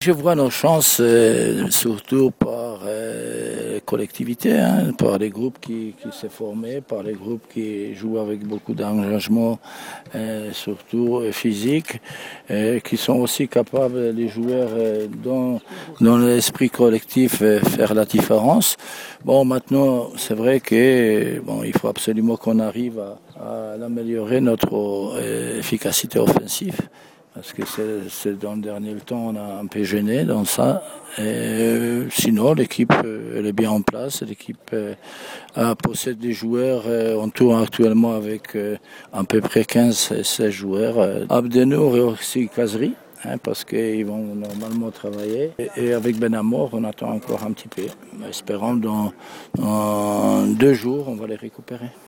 هنري كاسبارجاك: مدرب المنتخب الوطني